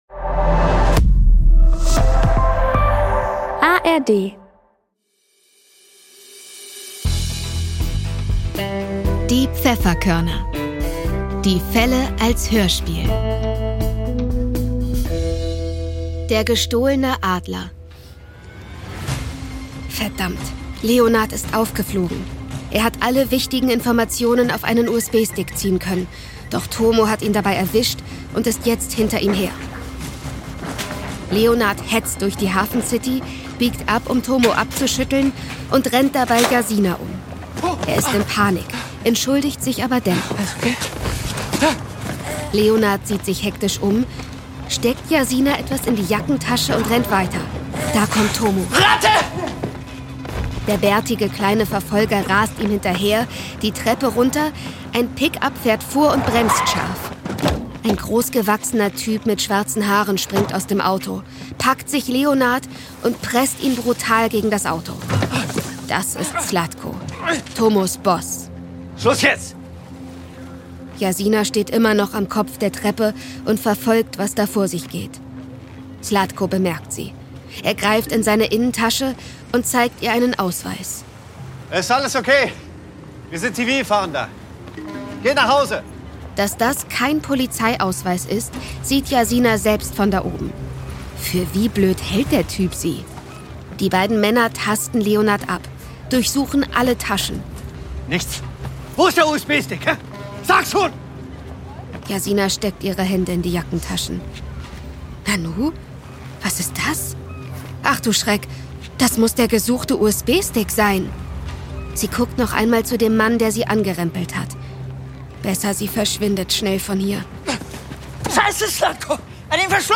Wie gefallen Dir die Hörspiele der Pfefferkörner?